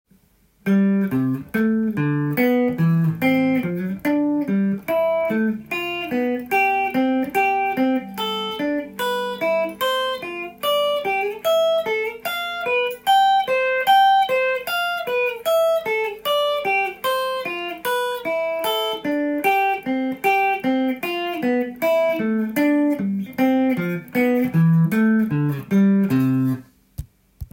度数ごとのCメジャースケール練習】
５度進行
ドからソ　レからラ　などが５度の並びになります。